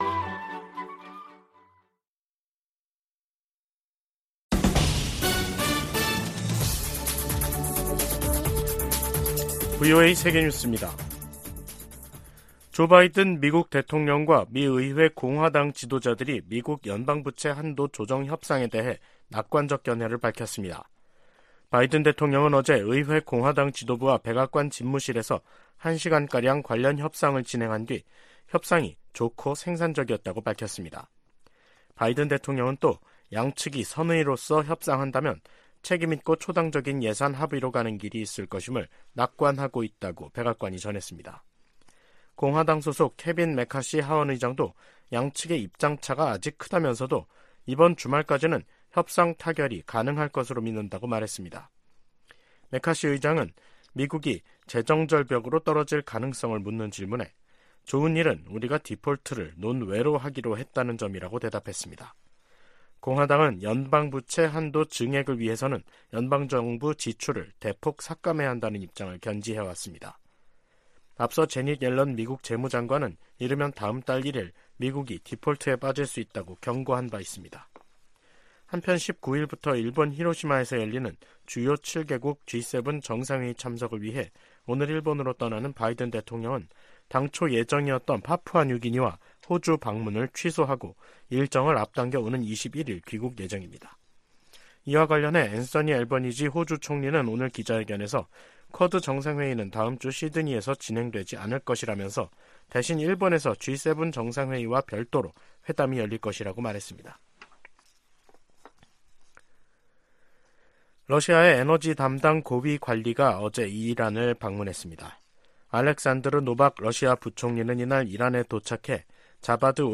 VOA 한국어 간판 뉴스 프로그램 '뉴스 투데이', 2023년 5월 17일 2부 방송입니다. 로이드 오스틴 미 국방장관은 상원 청문회에서 한국에 대한 확장억제 강화조치를 취하는 중이라고 밝혔습니다. 북한은 우주발사체에 위성 탑재 준비를 마무리했고 김정은 위원장이 '차후 행동계획'을 승인했다고 관영매체들이 전했습니다. 미 국무부가 화학무기금지협약(CWC) 평가회의를 맞아 북한이 생화학무기 프로그램을 보유하고 있다는 평가를 재확인했습니다.